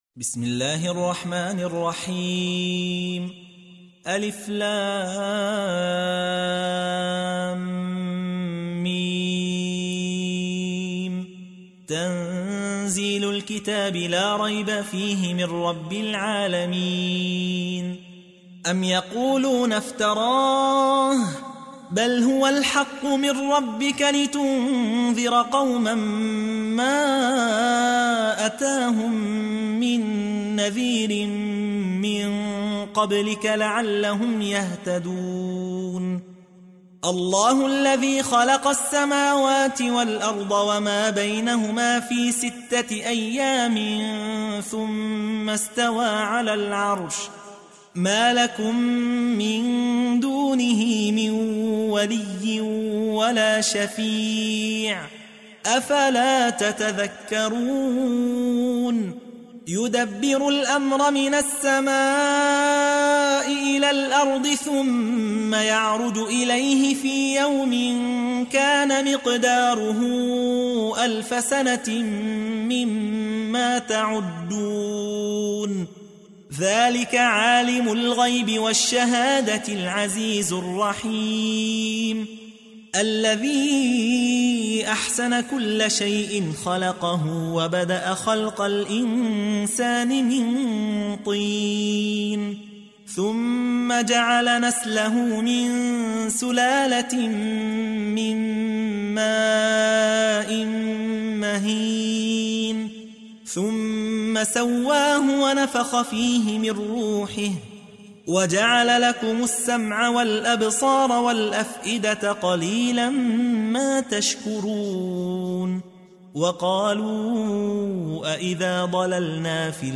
تحميل : 32. سورة السجدة / القارئ يحيى حوا / القرآن الكريم / موقع يا حسين